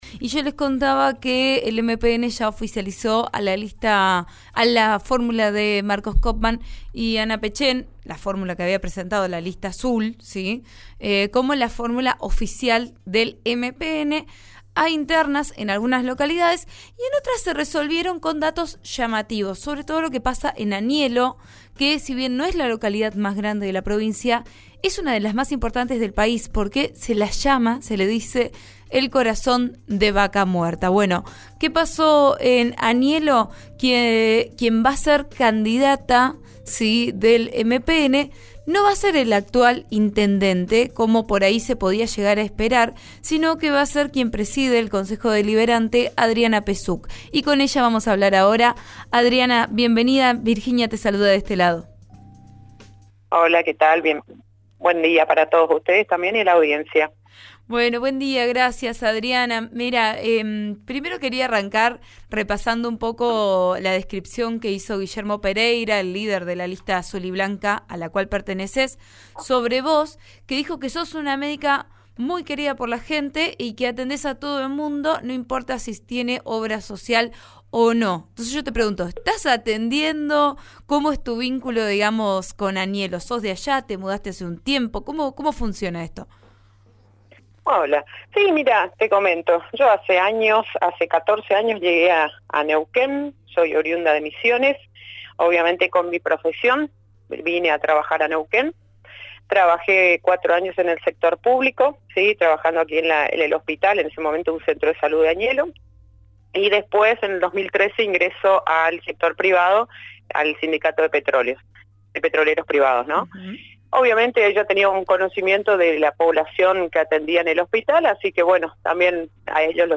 En una entrevista con Vos A Diario, por RN Radio, la dirigente relató que es oriunda de Misiones y llegó a la ciudad hace 14 años.